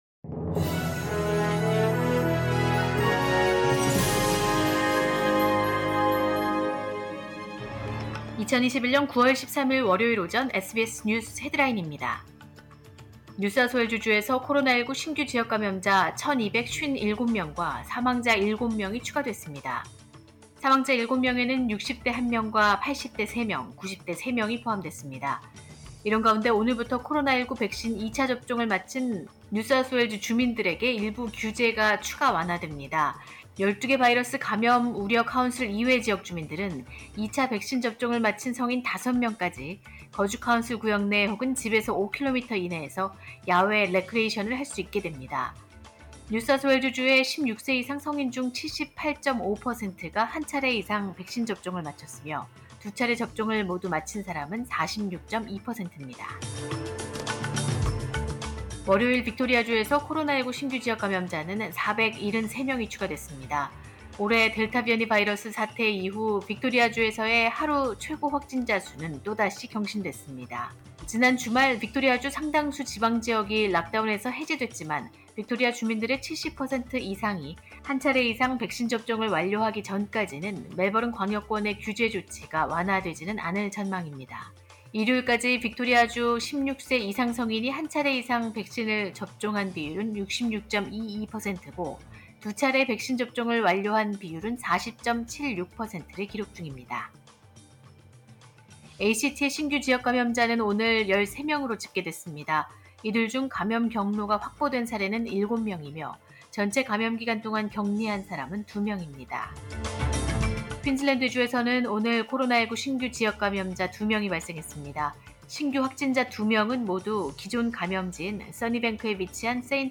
2021년 9월 13일 월요일 오전의 SBS 뉴스 헤드라인입니다.